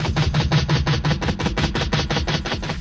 RI_DelayStack_85-03.wav